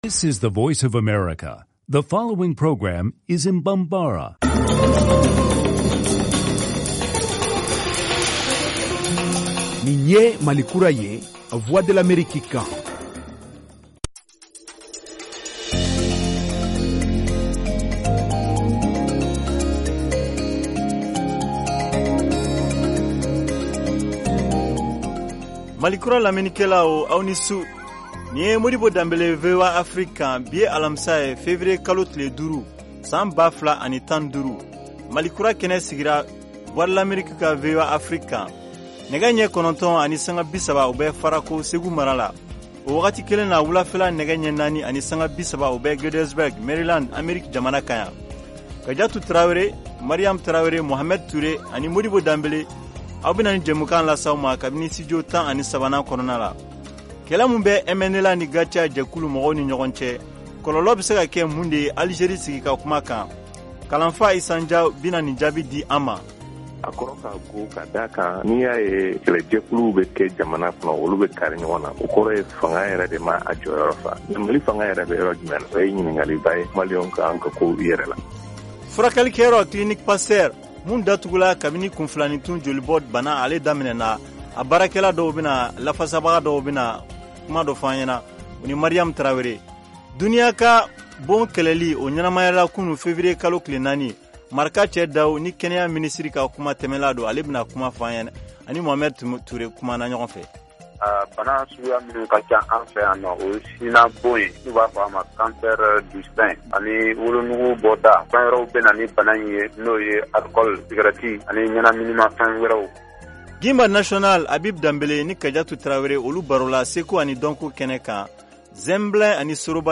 Emission quotidienne
en direct de Washington, DC, aux USA.